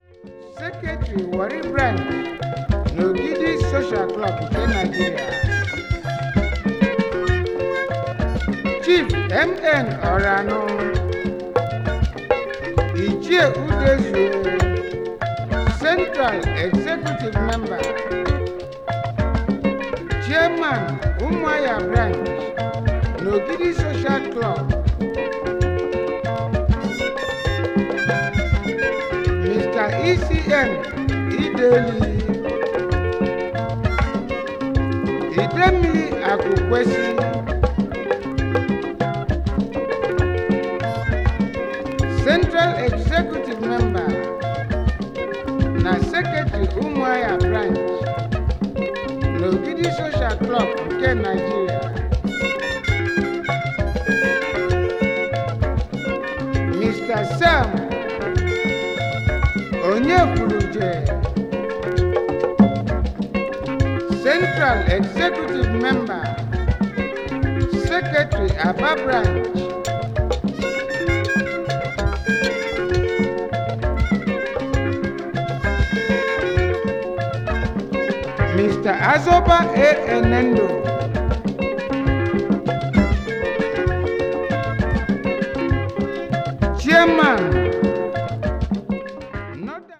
B面のホッコリゆるやかな3ビートとスポークン・ワードによるハイライフもグルーヴィで最高です。